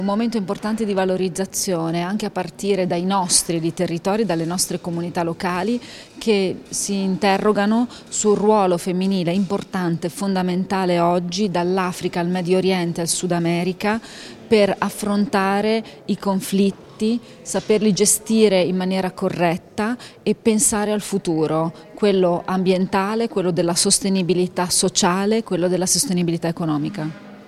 “Donne In Difesa Di”: oggi la presentazione con l'assessora Ferrari